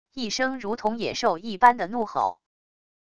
一声如同野兽一般的怒吼wav音频